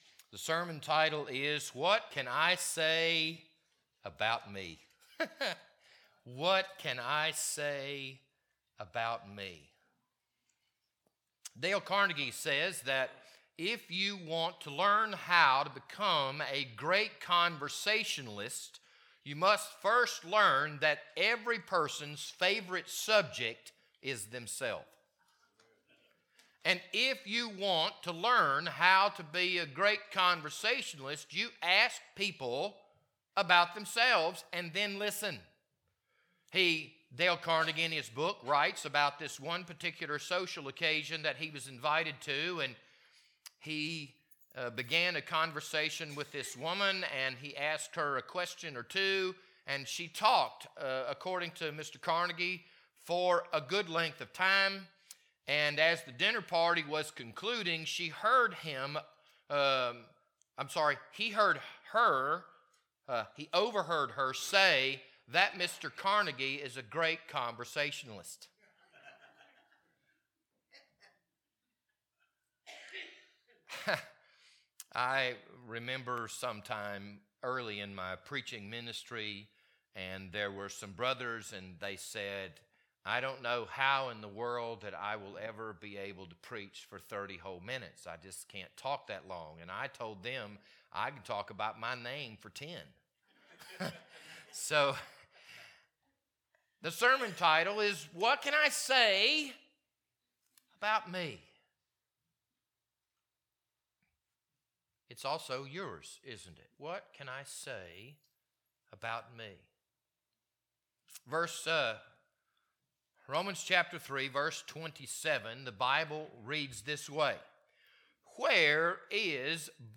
This Sunday morning sermon was recorded on February 4th, 2024.